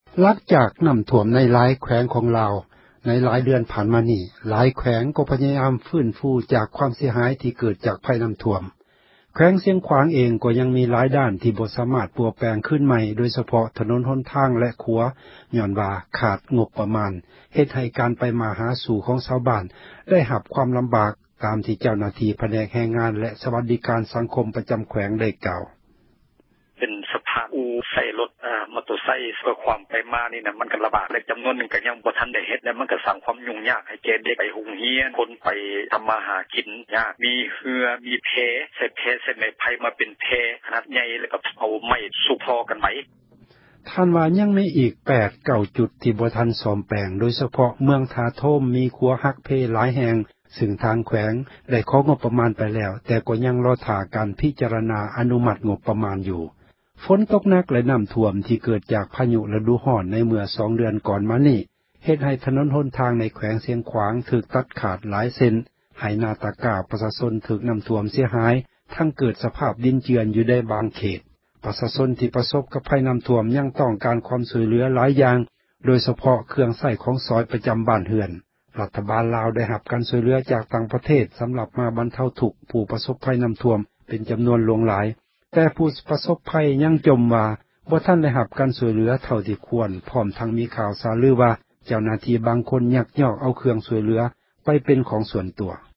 ດັ່ງ ເຈົ້າໜ້າທີ່ ຜແນກ ແຮງງານ ແລະ ສວັດດີ ການ ສັງຄົມ ປະຈໍາແຂວງ ເວົ້າວ່າ: